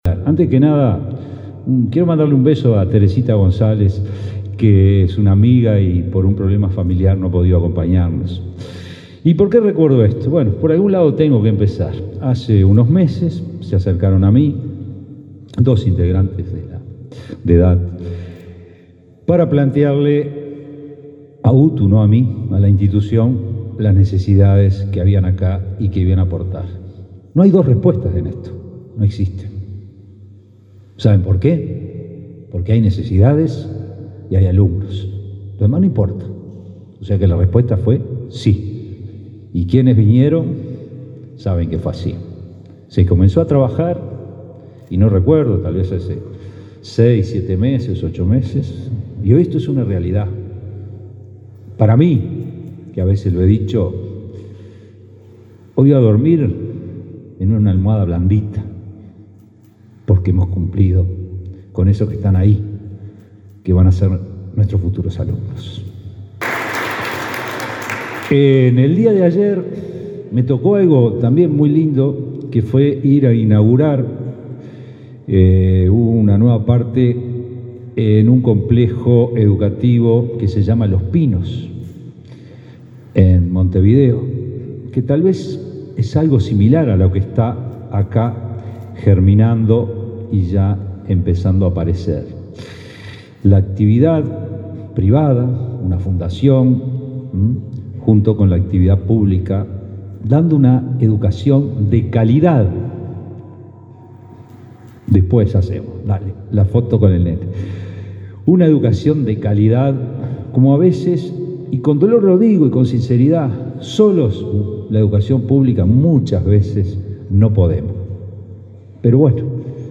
Palabras del director general de UTU
Palabras del director general de UTU 29/06/2023 Compartir Facebook X Copiar enlace WhatsApp LinkedIn La UTU firmó un convenio con la Fundación DAT Carlos Reyles, de Durazno, que permitirá que ambas puedan llevar adelante programas, proyectos, actividades y cursos sobre temas de interés. El director general de la UTU, Juan Pereyra, remarcó la importancia del acuerdo.